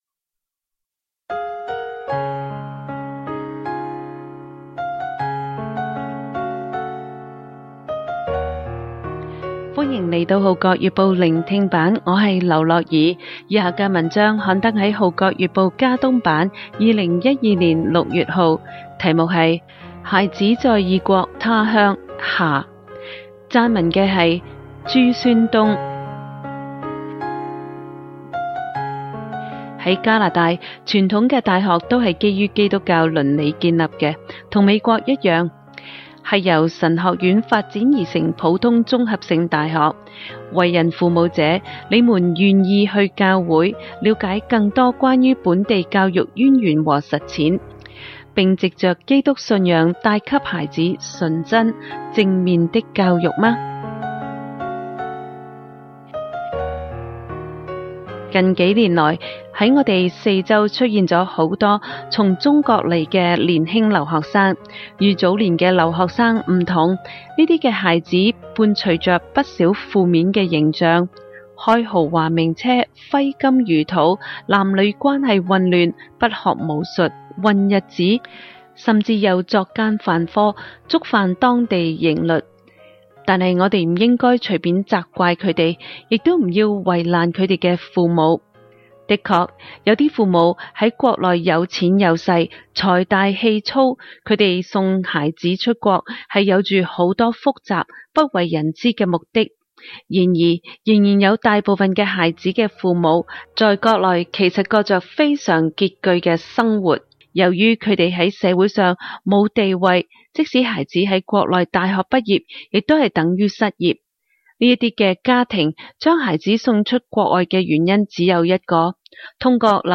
聆聽版/Audio孩子在異國他鄉 (下) 家庭頻道